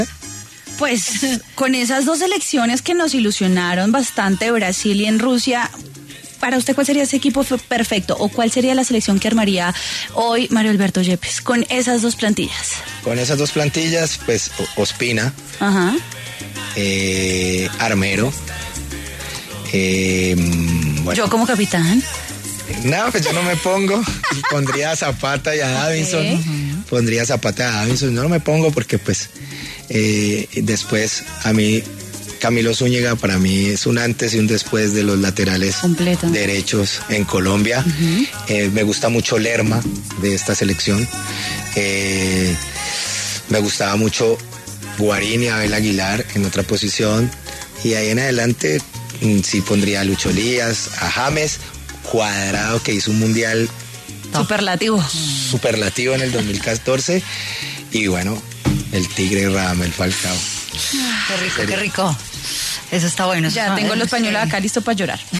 Mario Alberto Yepes en su entrevista con Mujeres W reveló cual son los 11 jugadores históricos de la selección Colombia con los que el armaría una plantilla.